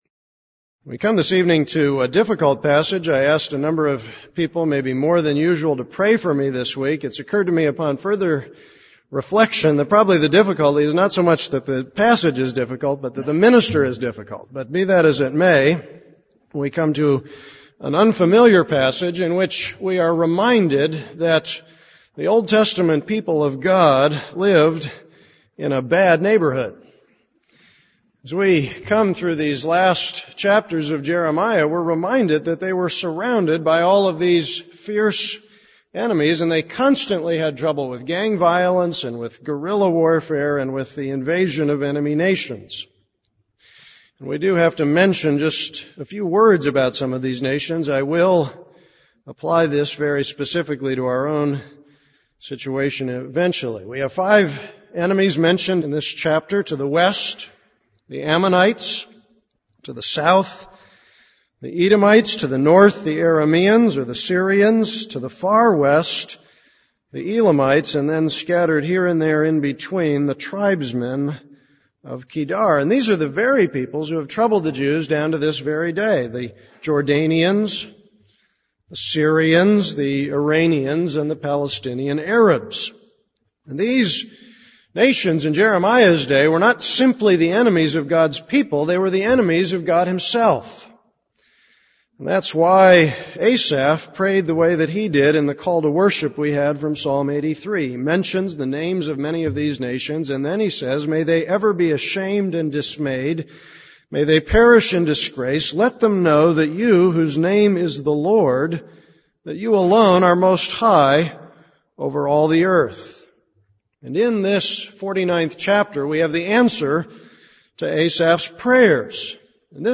This is a sermon on Jeremiah 49:1-39.